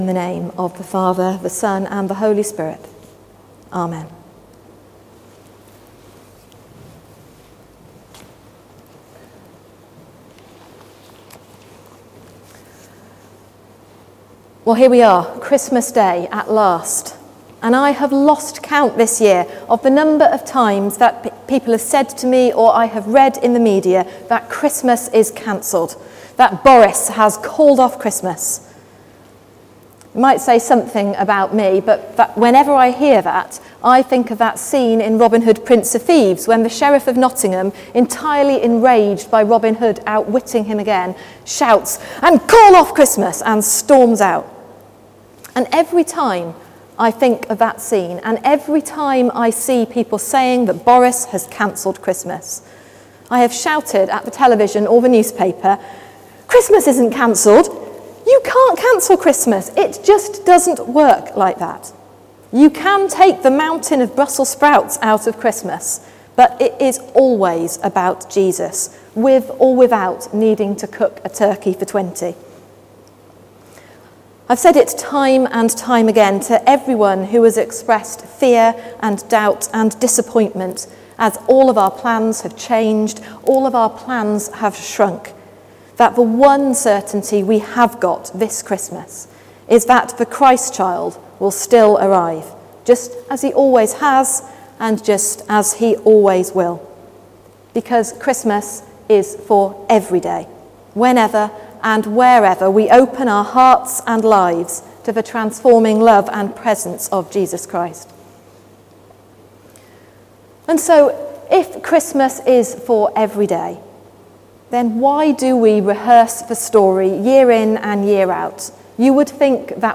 Sermon: Same Old Story | St Paul + St Stephen Gloucester